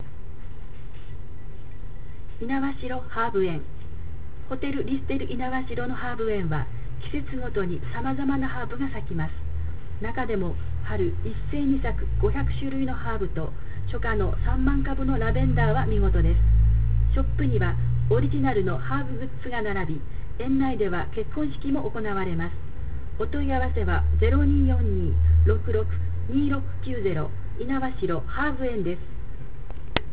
音声案内